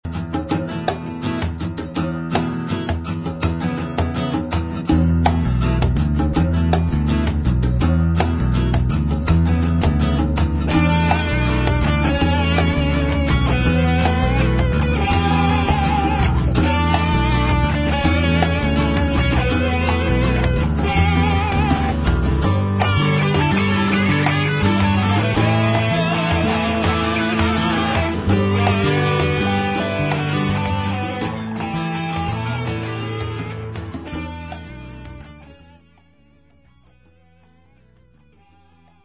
Guitar
Vocals / Drums
Bass Guitar
Keyboards
Percussion